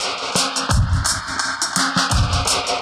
Index of /musicradar/dub-designer-samples/85bpm/Beats
DD_BeatFXB_85-02.wav